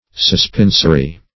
suspensory - definition of suspensory - synonyms, pronunciation, spelling from Free Dictionary
Suspensory \Sus*pen"so*ry\, a.